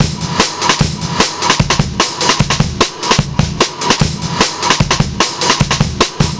Index of /90_sSampleCDs/Zero-G - Total Drum Bass/Drumloops - 1/track 01 (150bpm)